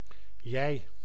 Ääntäminen
IPA: /dʉː/ IPA: [d̪ʉː]